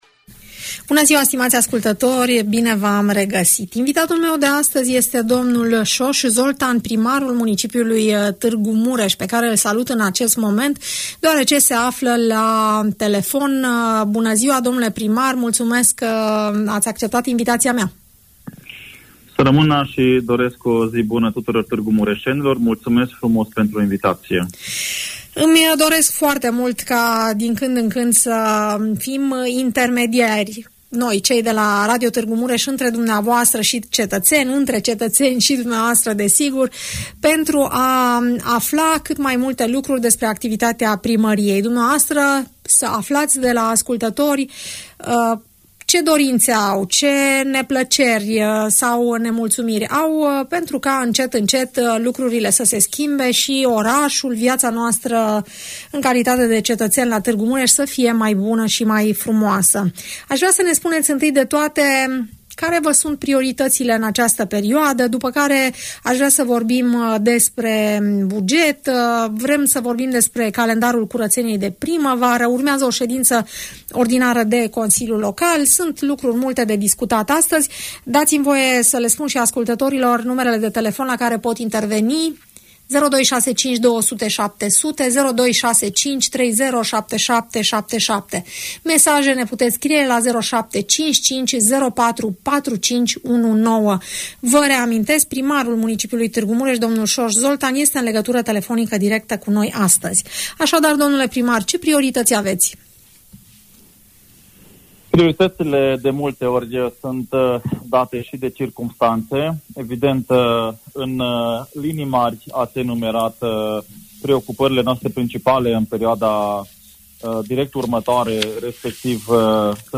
Soos Zoltan, primarul municipiului Tg. Mureș, în direct la RTM